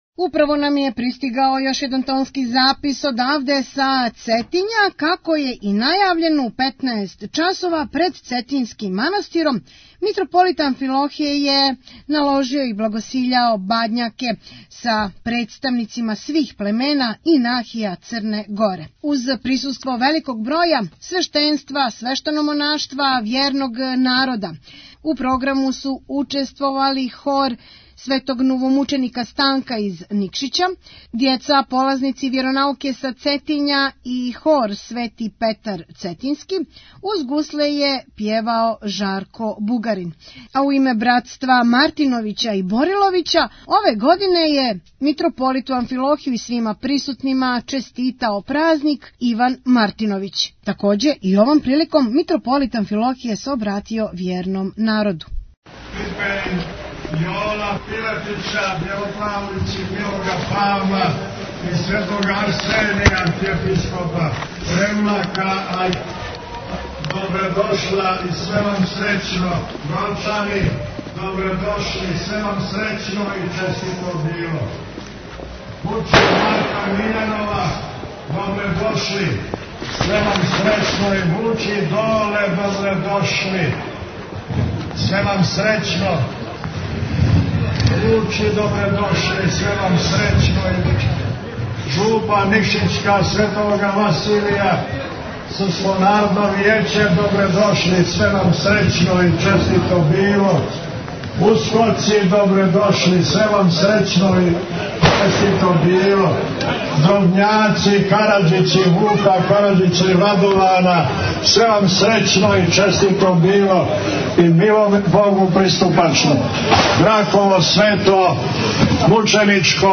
Митрополит Амфилохије налагао је бадњаке пред Цетињским манастиром Tagged: Бесједе Download the file . 28:00 минута (4.81 МБ) Архиепископ цетињски Митрополит црногорско - приморски господин Амфилохије налагао је бадњаке пред Цетињским манастиром са представницима свих племена и нахија Црне Горе.